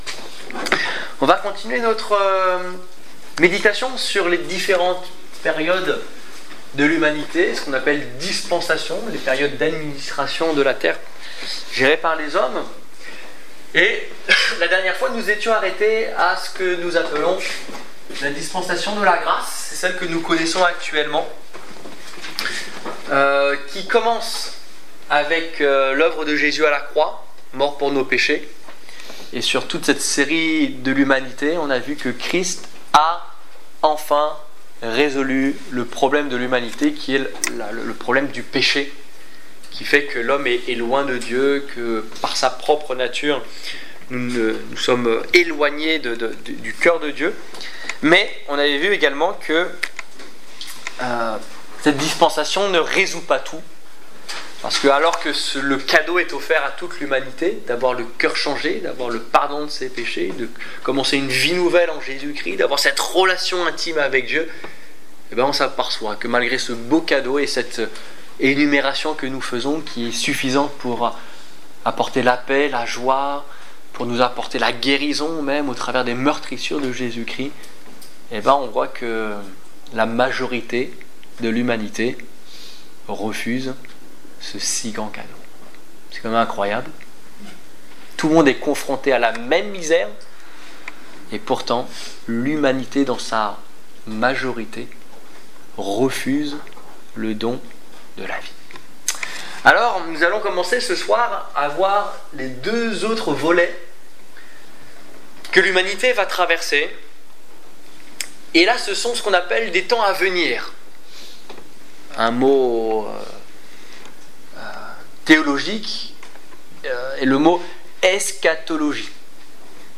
Étude biblique du 15 avril 2015